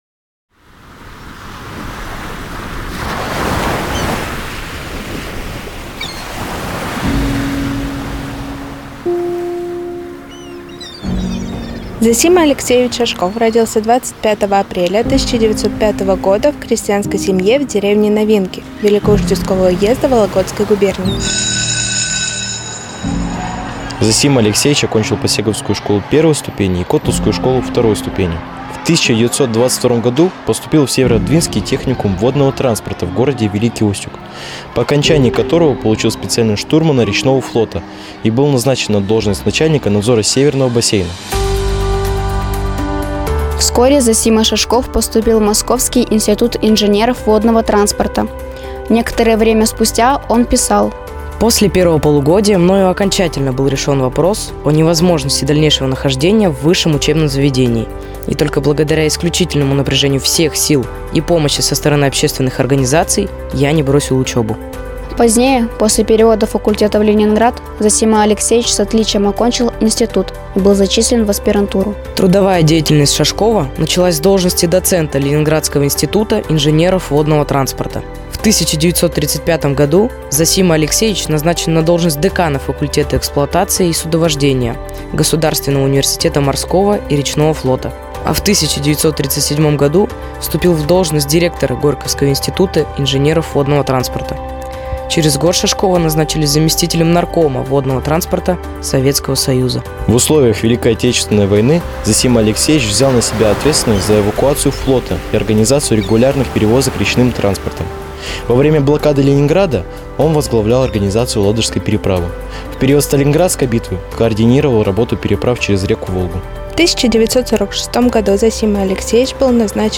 Аудиогид З.А. Шашков